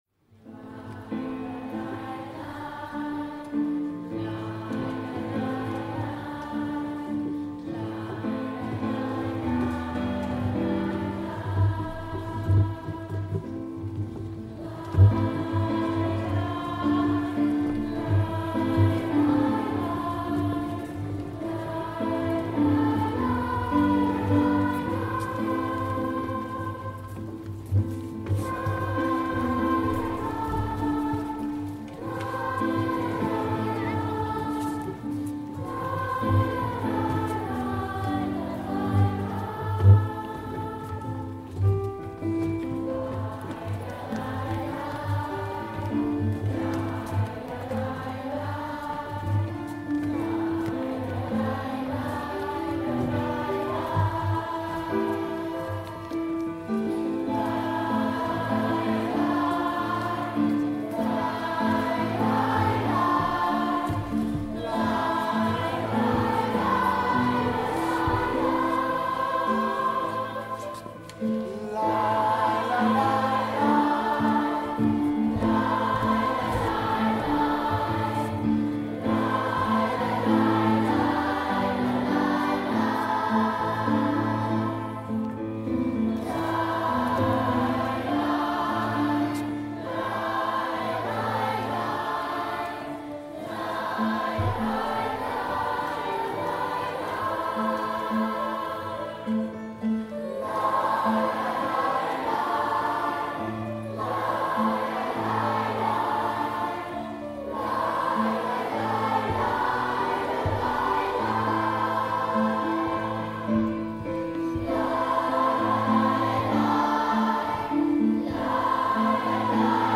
Headliner Embed Embed code See more options Share Facebook X Subscribe We open the Holocaust Memorial 2020 civic event with the singing together of the Hebrew Lament with singers from Sing! Community Choir, the CAP singers from Cambridge Academic Partnership and Primary Singers from Fawcett Primary, Trumpington Meadows Primary, St Matthew's Primary, Milton Road Primary, and The Spinney.